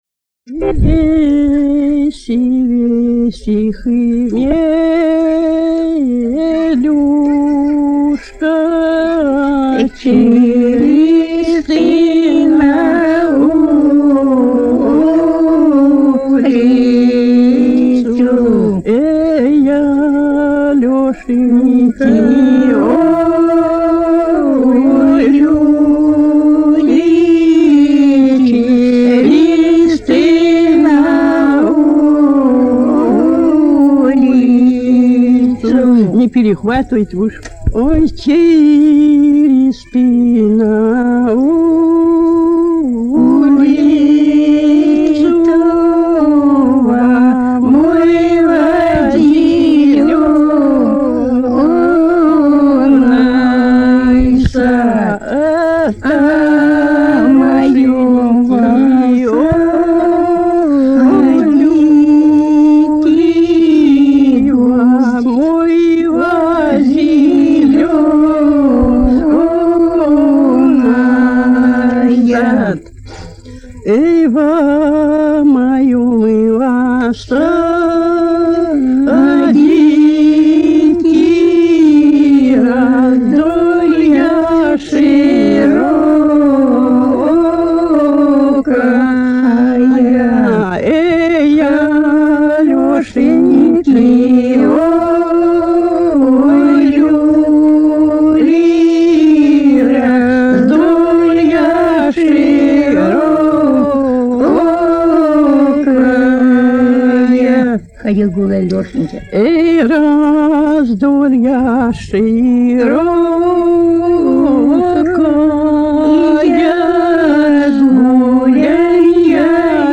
свадебная